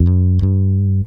Bass_76.wav